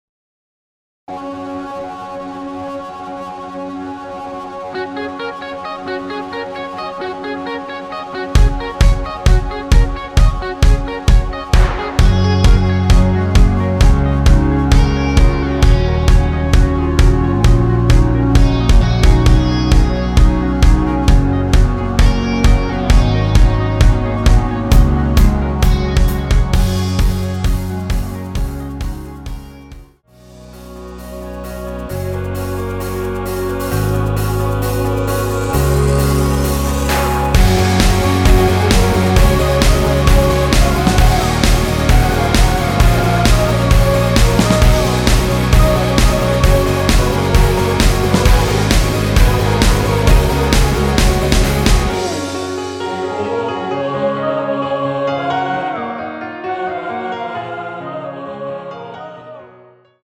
원키에서(-3)내린 멜로디 포함된 MR입니다.(미리듣기 확인)
Gb
앞부분30초, 뒷부분30초씩 편집해서 올려 드리고 있습니다.
중간에 음이 끈어지고 다시 나오는 이유는